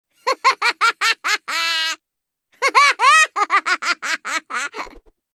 Risada Gnar (LoL)
Risada de Gnar de League Of Legends. Gnar é um yordle primitivo cujas artimanhas brincalhonas podem irromper em uma raiva infantil em um instante, transformando-o em uma besta gigantesca determinada a destruir.
risada-gnar-lol.mp3